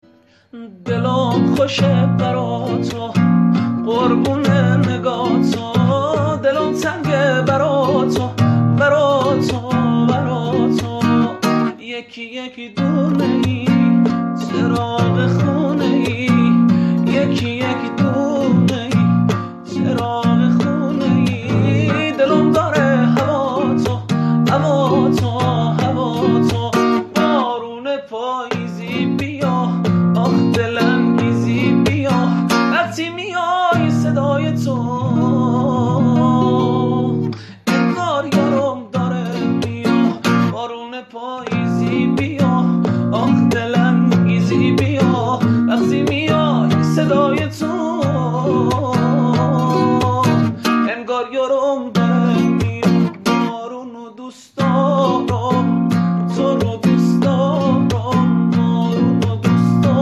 آهنگ محلی
با گیتار